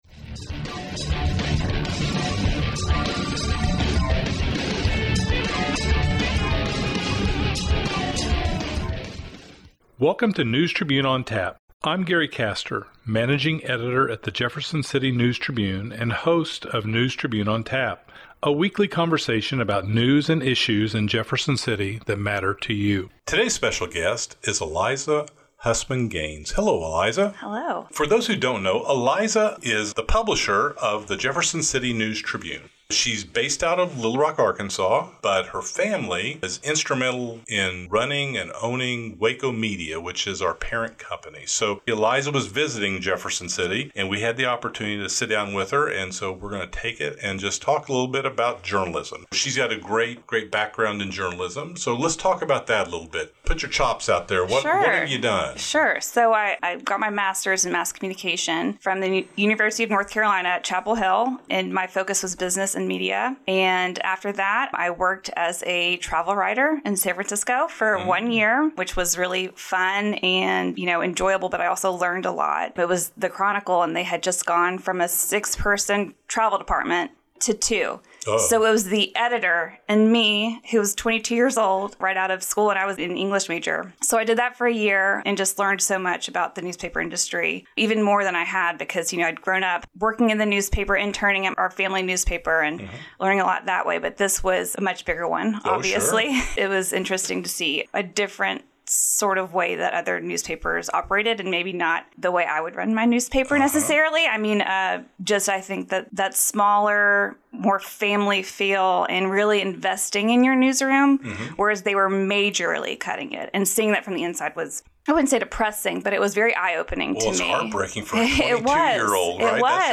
Putting readers first -- A conversation